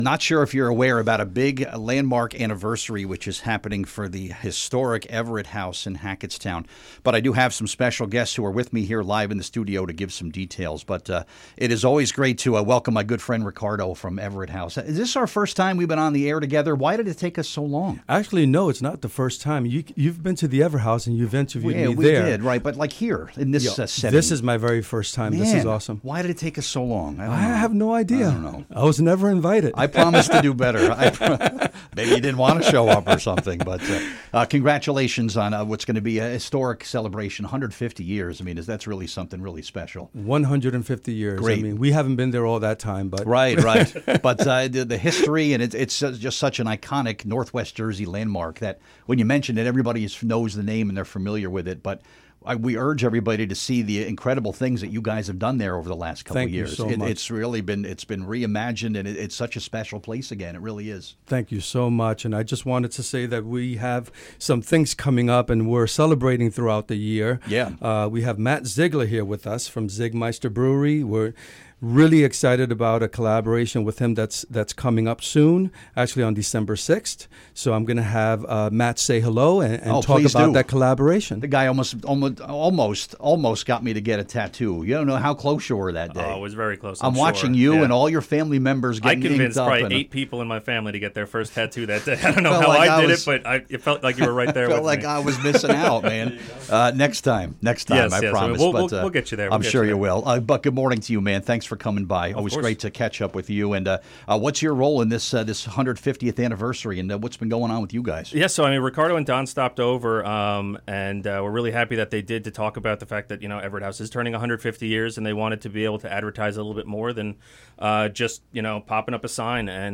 Radio Spot WRNJ Radio Interview - 150th Anniversary